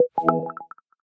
menu-play-click.ogg